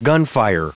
Prononciation du mot gunfire en anglais (fichier audio)
Prononciation du mot : gunfire